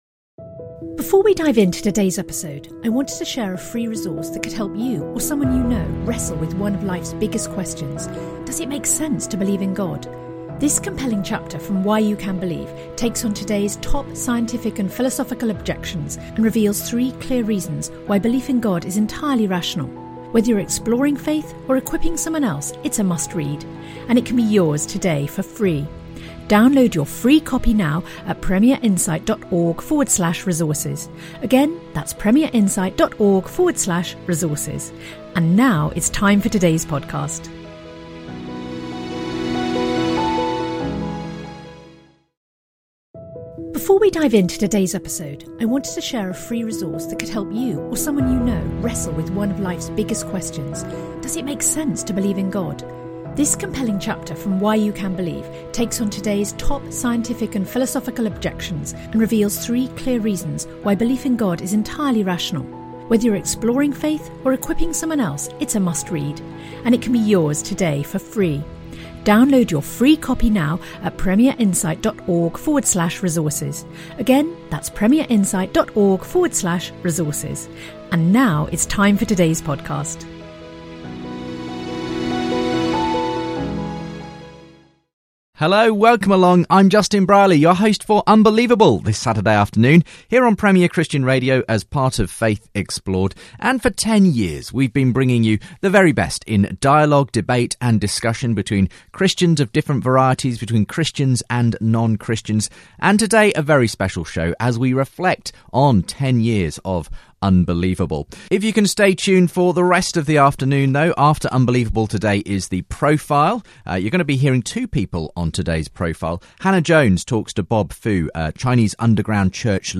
We also hear some memorable moments from shows over the years, Birthday greetings form selected friends and audio form the very first edition of the programme.